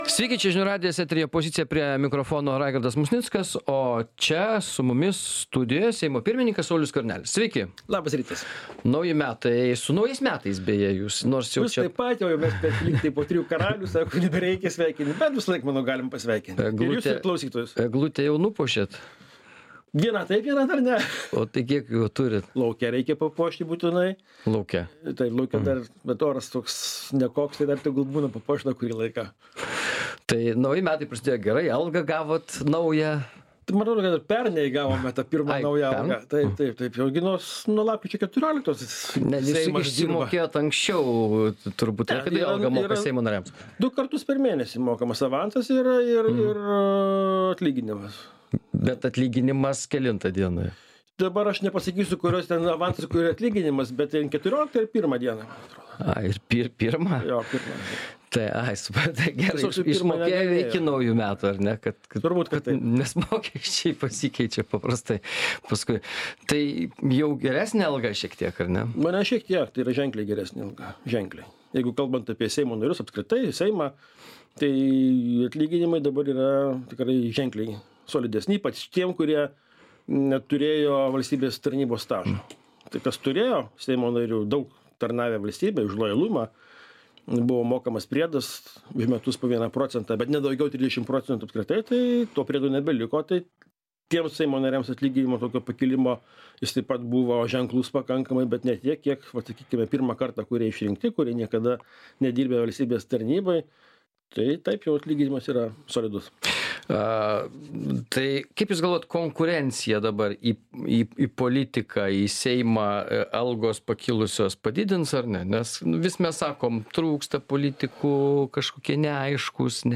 Laidoje dalyvauja Seimo pirmininkas Saulius Skvernelis.